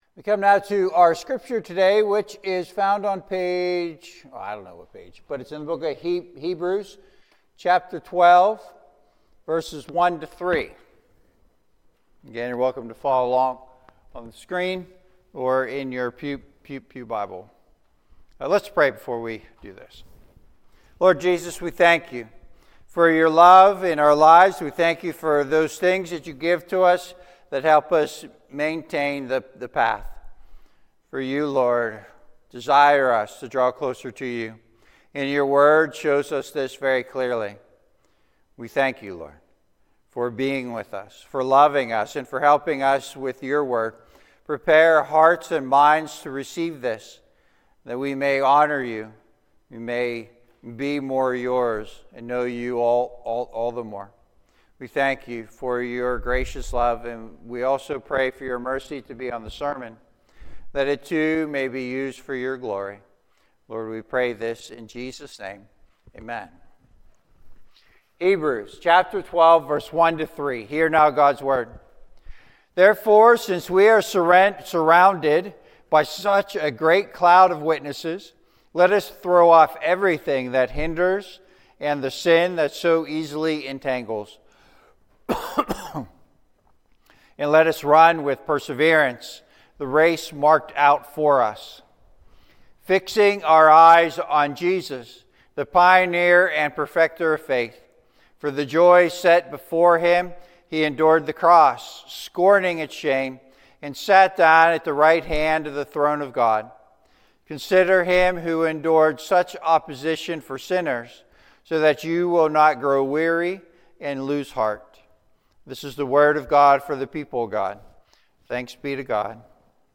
1-3 Service Type: Sunday AM « So What Part Are We?